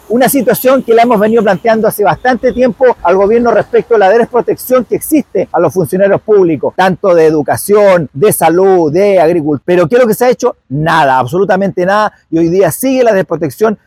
El diputado de Renovación Nacional Jorge Rathgeb señaló que esta es una situación cotidiana, ya advertida al Gobierno, sin respuesta hasta ahora.